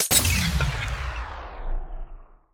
Minecraft Version Minecraft Version 1.21.5 Latest Release | Latest Snapshot 1.21.5 / assets / minecraft / sounds / block / respawn_anchor / deplete1.ogg Compare With Compare With Latest Release | Latest Snapshot
deplete1.ogg